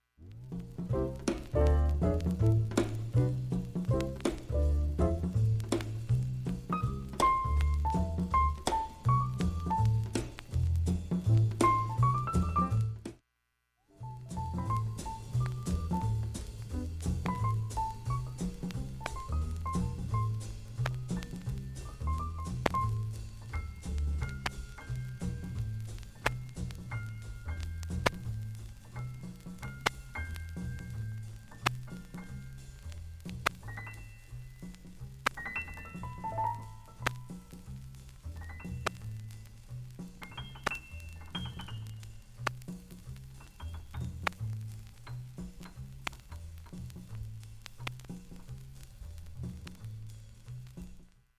単発のかすかなプツが５箇所
起用したトリオ編成の作品